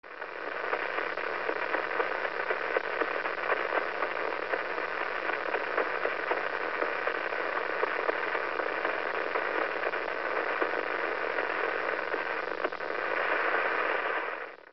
Звуки пульсаров
На этой странице вы можете слушать и скачивать звуки пульсаров — завораживающие космические сигналы, преобразованные в аудио. Эти ритмичные импульсы, испускаемые нейтронными звездами, создают необычную атмосферу, напоминающую о бескрайности Вселенной.
Звук межзвездного пульсара